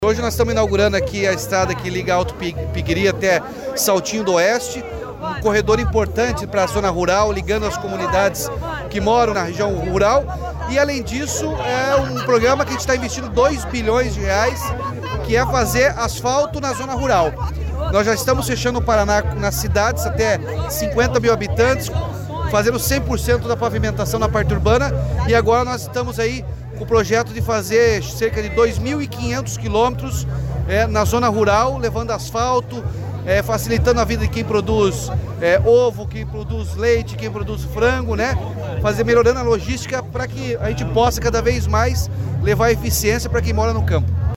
Sonora do governador Ratinho Junior sobre a pavimentação que vai facilitar escoamento agrícola em Alto Piquiri